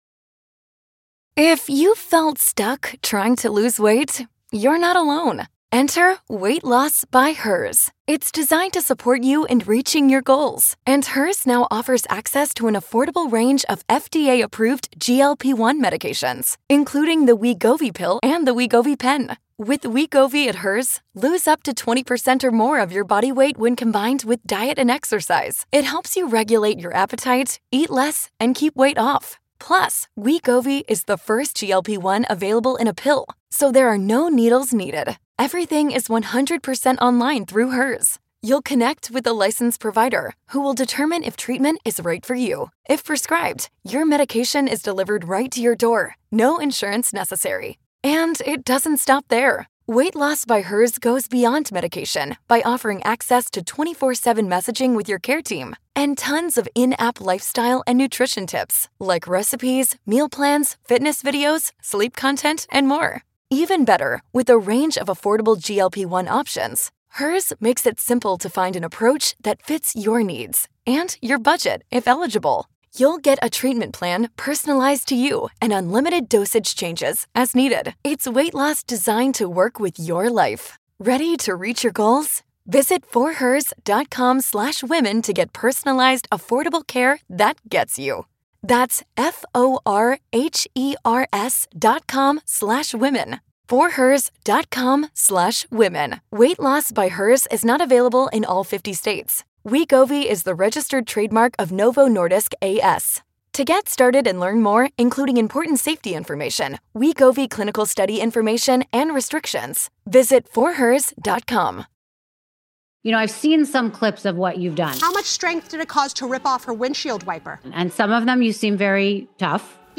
Don’t miss this engaging conversation with one of today’s most insightful legal minds.